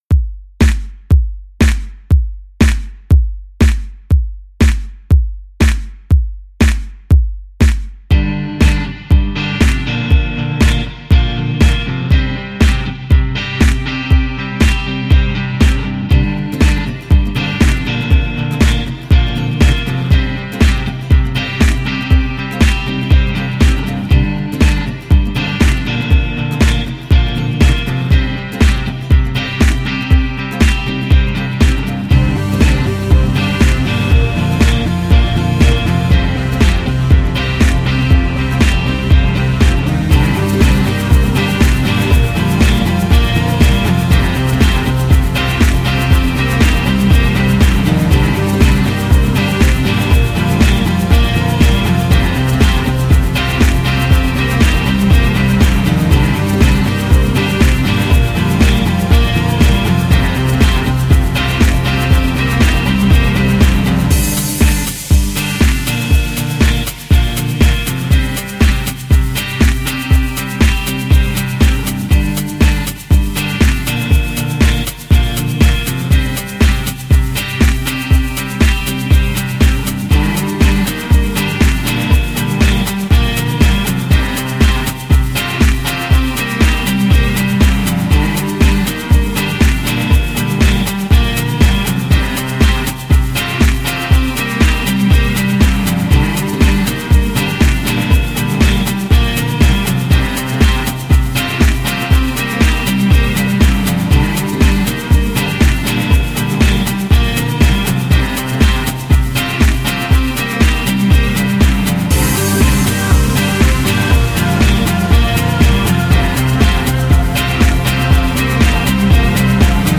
Шум моделей на подиуме